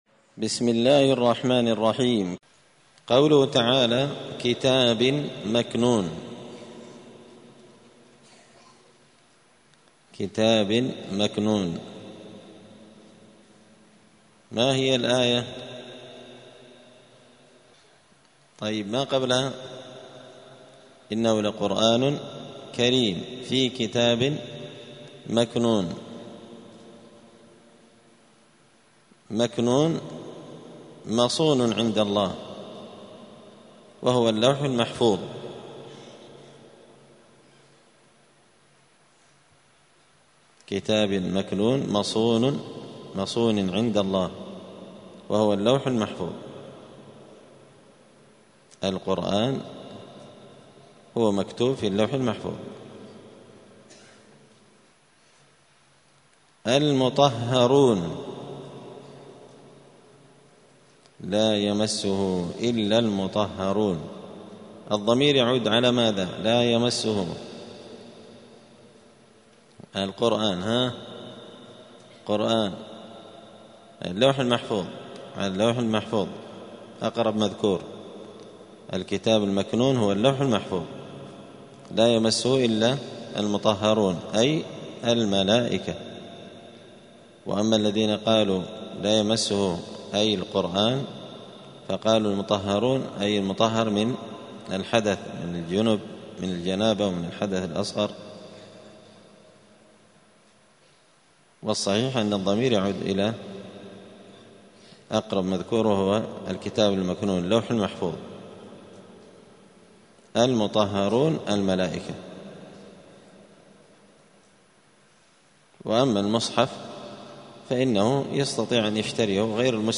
زبدة الأقوال في غريب كلام المتعال الدرس الثاني والتسعون بعد المائة (192)